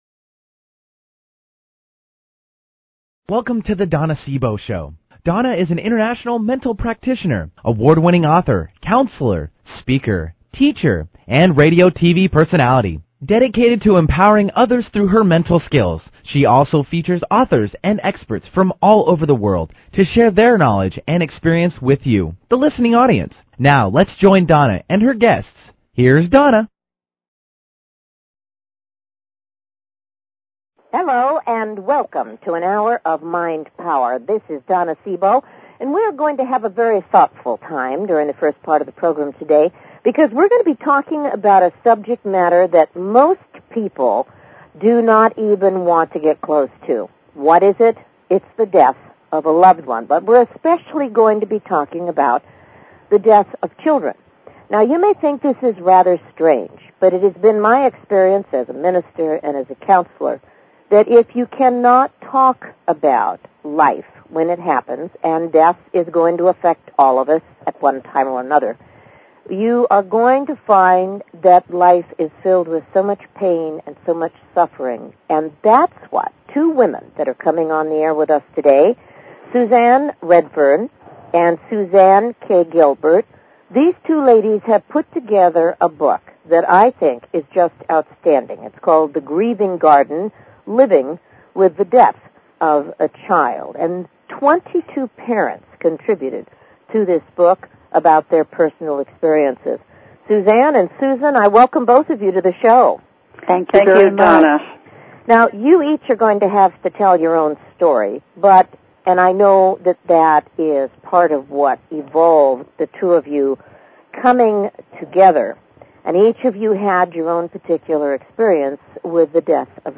Talk Show Episode
Join us for an in-depth conversation on a heart moving subject.
Callers are welcome to call in for a live on air psychic reading during the second half hour of each show.